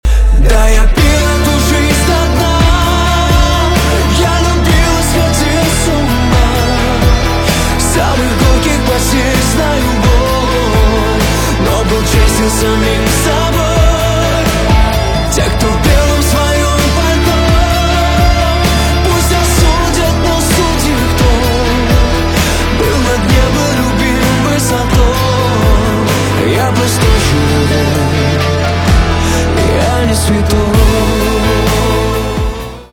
поп
грустные
гитара , барабаны